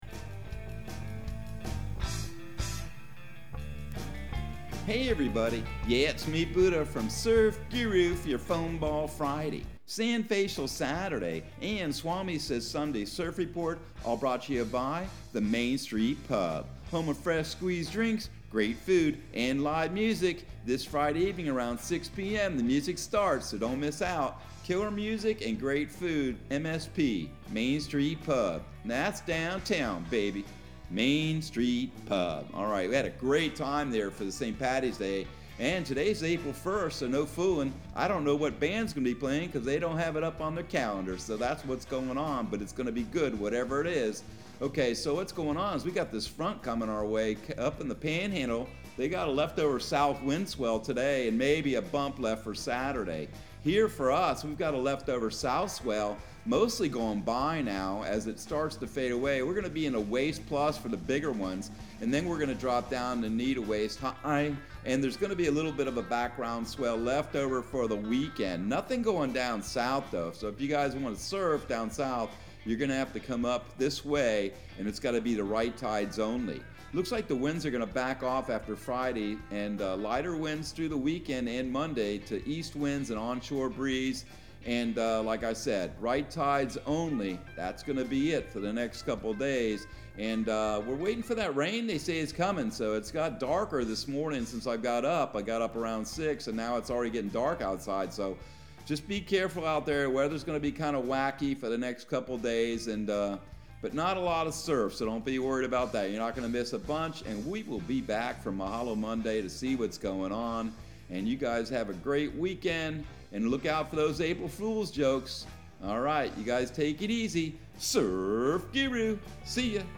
Surf Guru Surf Report and Forecast 04/01/2022 Audio surf report and surf forecast on April 01 for Central Florida and the Southeast.